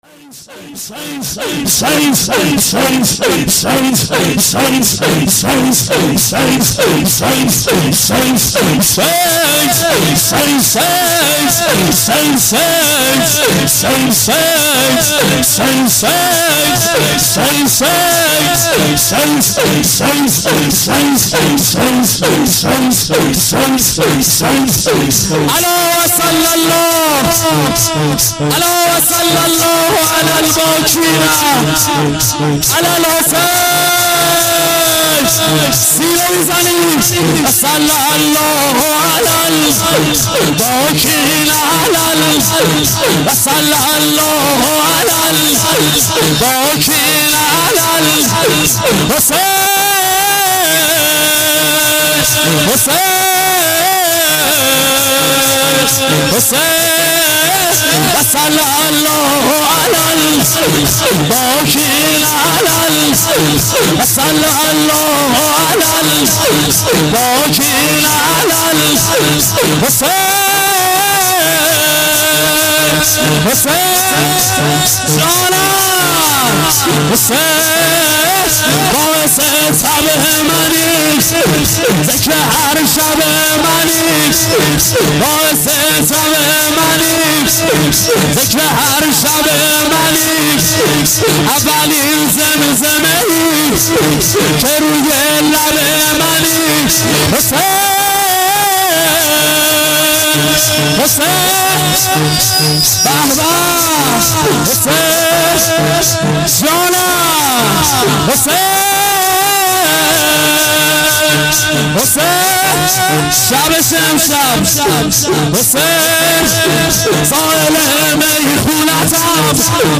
شور - صلی الله علی الباکین علی الحسین